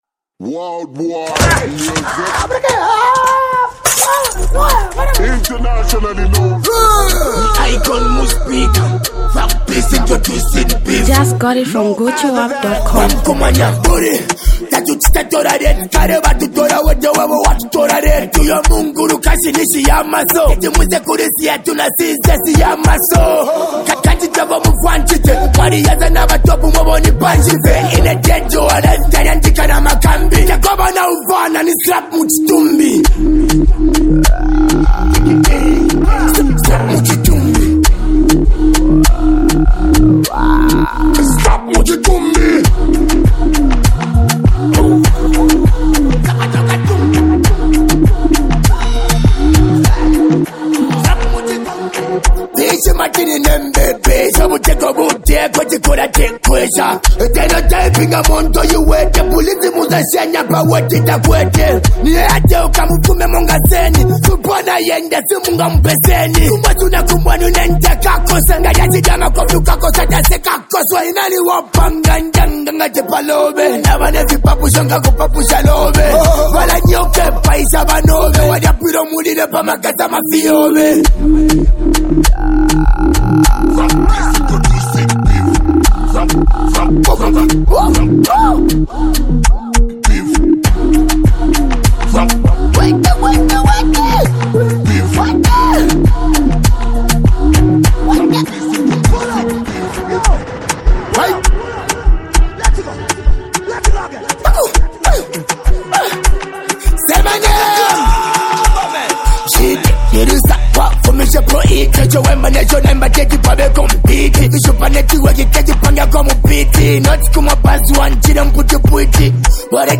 Highly mastered
Diss Track Song